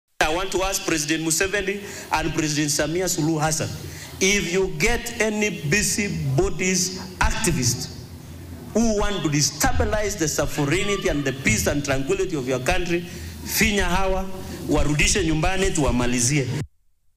Speaking during a Sunday service at the PAG Church in Kapsabet town, Senator Cherargei stated that foreign nations should not tolerate individuals who exploit the guise of human rights protection to jeopardize their security.